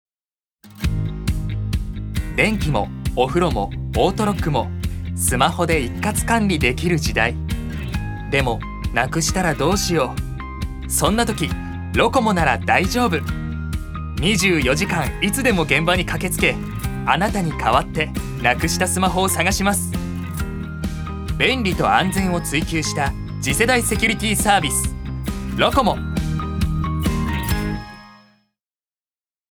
所属：男性タレント
ナレーション３